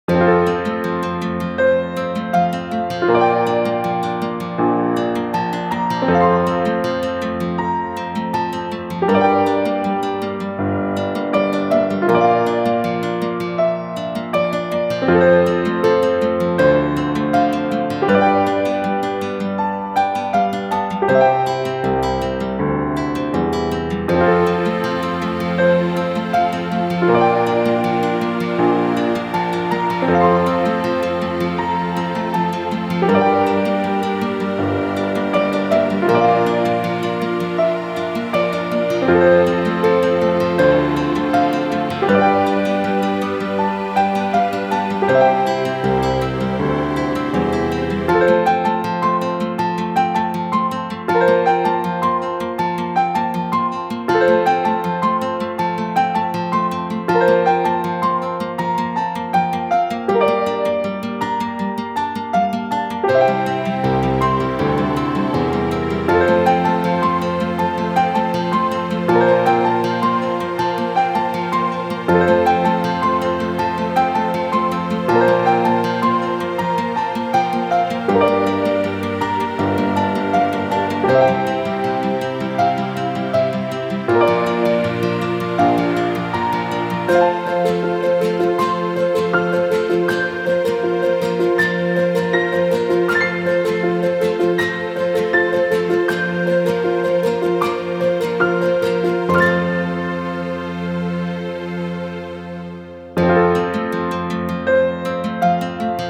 ogg(L) まったり 癒し ピアノ ギター
癒しのピアノをギターに乗せて。